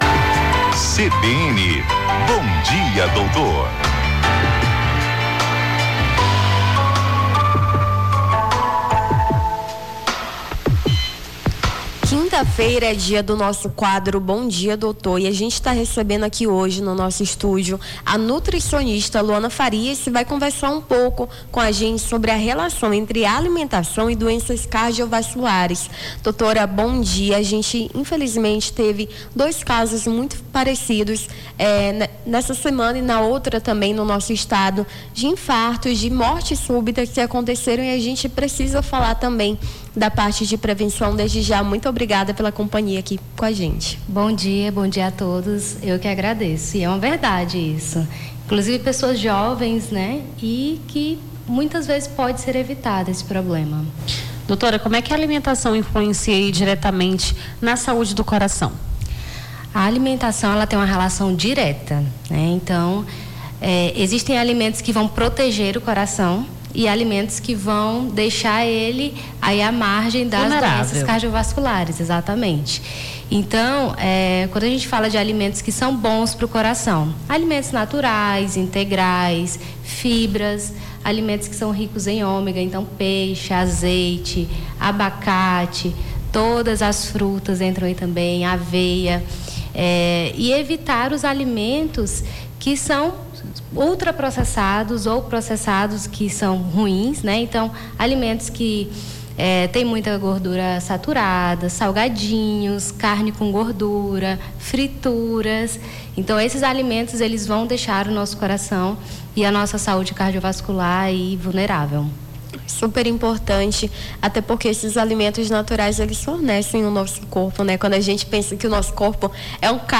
AO VIVO: Confira a Programação